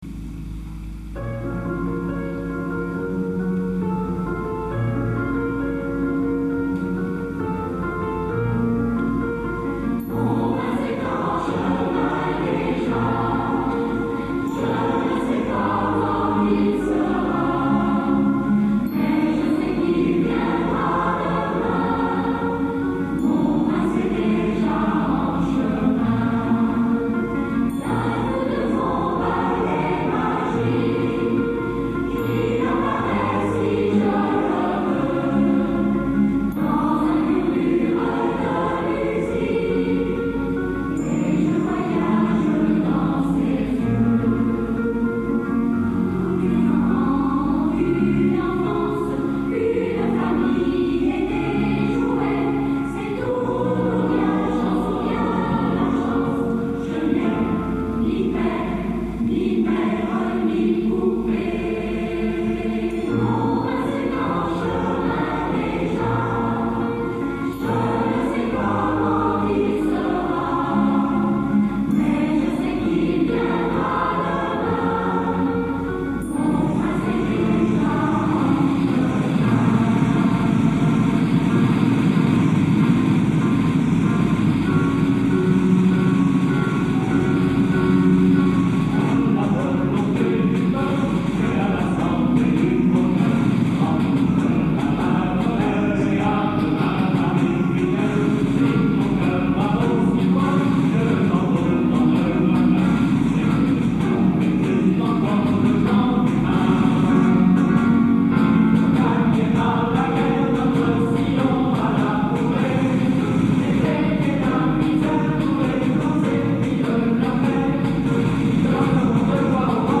Extraits audio du Concert du 13 mai 1977